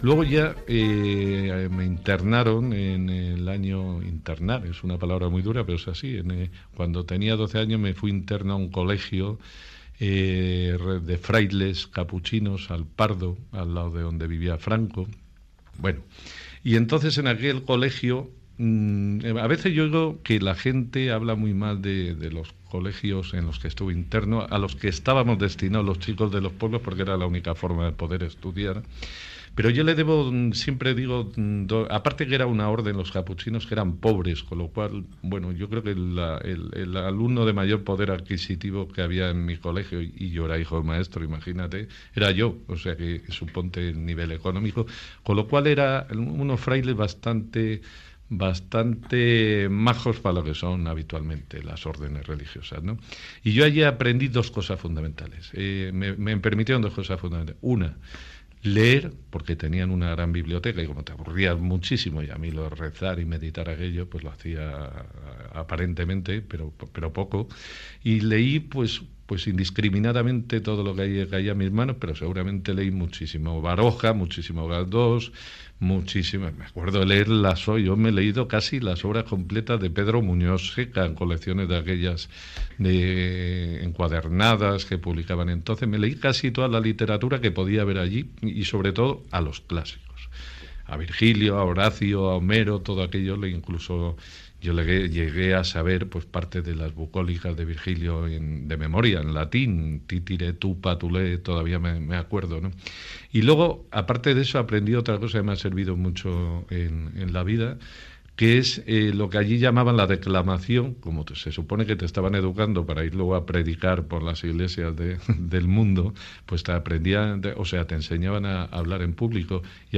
Fragment d'una entrevista a l'escriptor Julio Llamazares que recorda la seva etapa internat en una escola de El Pardo (Madrid)
Programa presentat per Juan Cruz.